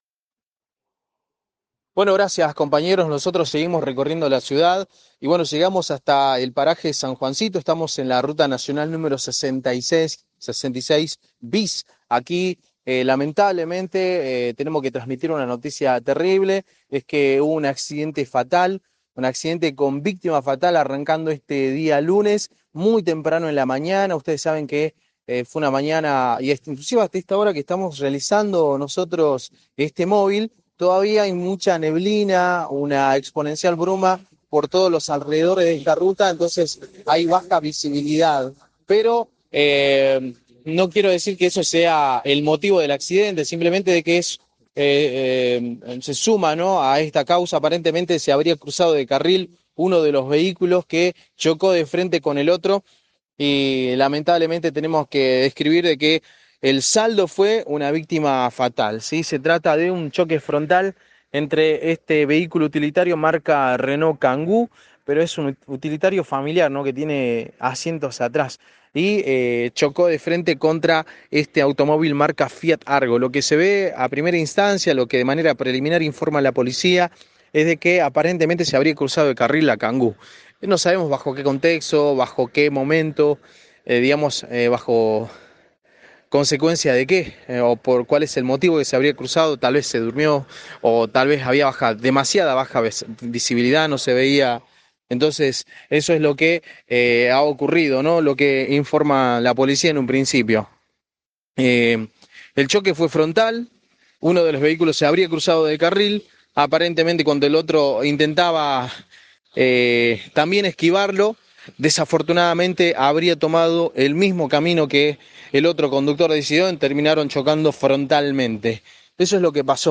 INFORME: